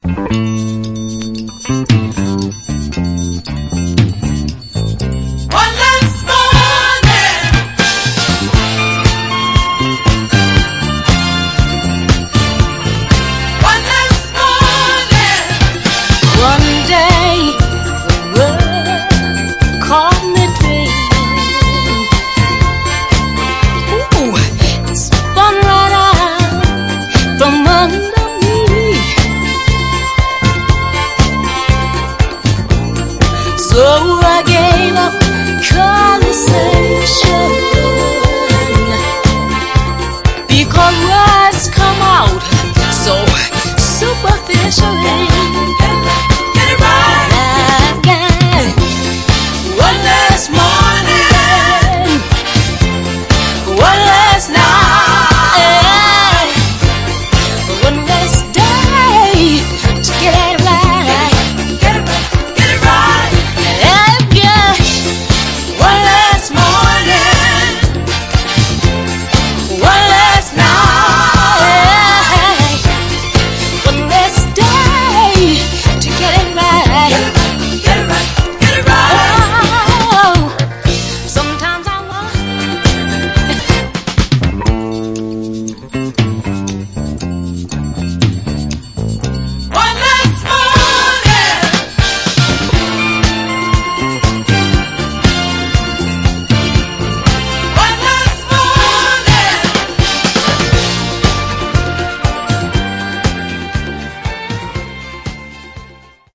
暖かみのある高揚感を持つソウル・チューン
※試聴音源は実際にお送りする商品から録音したものです※